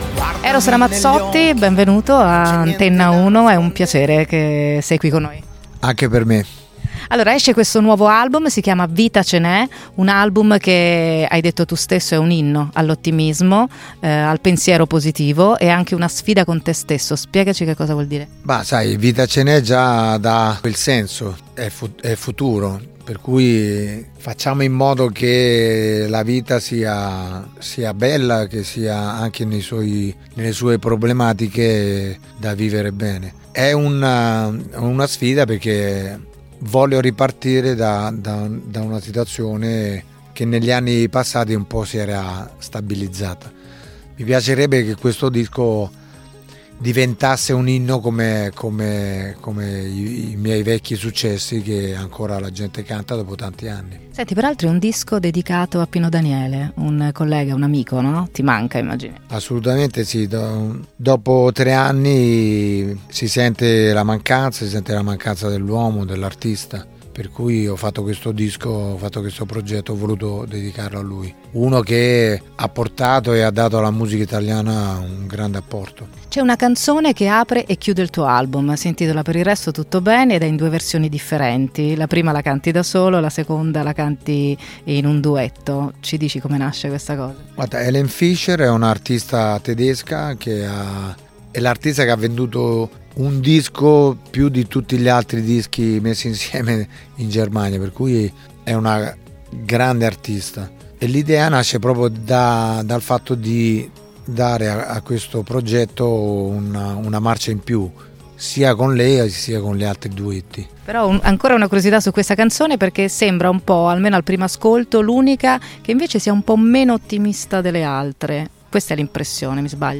Antenna1 Intervista in esclusiva con Eros Ramazzotti "Vita c'e ne" 2018
Patrizia Rossetti con Antenna1 intervistano Eros Ramazzotti